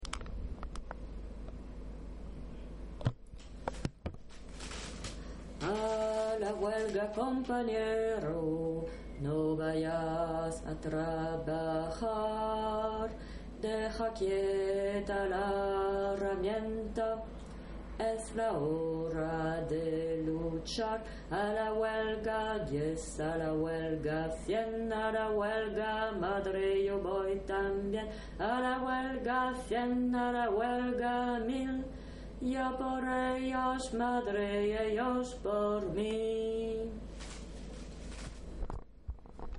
A-la-huelga_basses-2.mp3